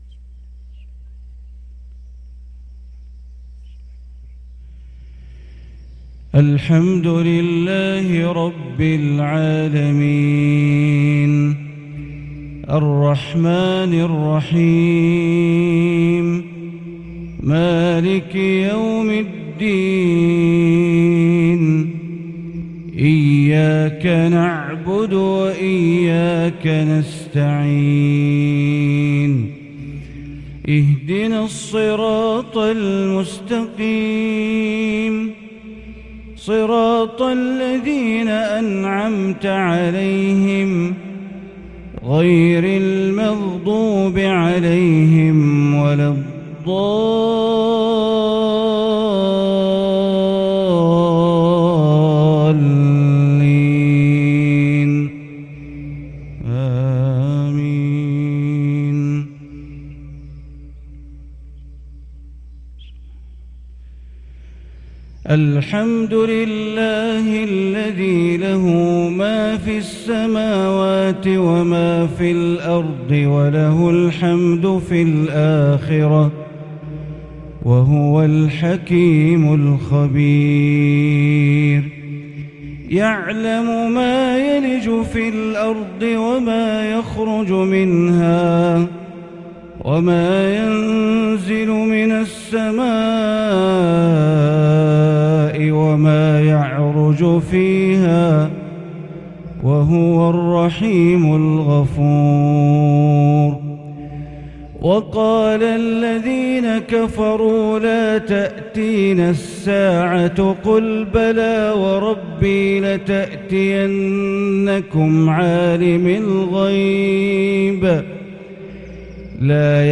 فجر الخميس 2-7-1443هـ فواتح سورة سبأ | Fajr prayer from surat Al-Saba 3-2-2022 > 1443 🕋 > الفروض - تلاوات الحرمين